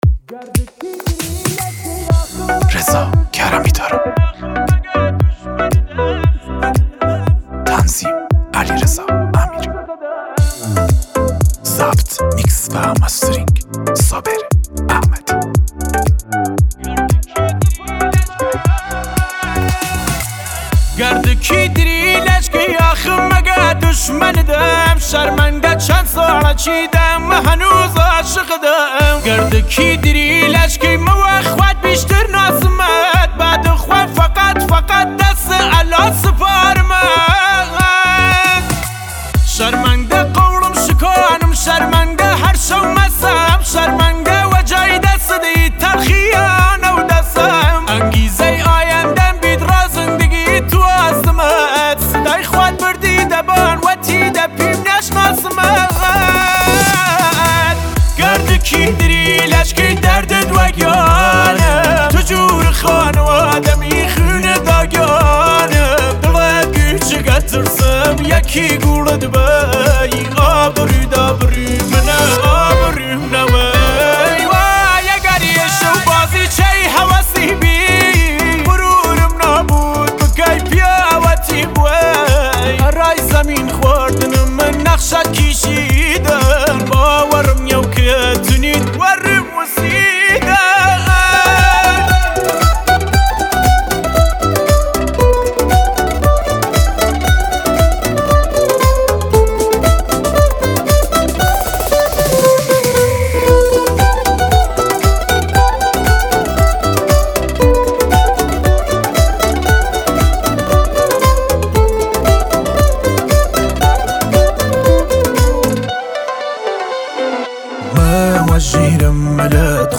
ریمیکس بیس دار تند کردی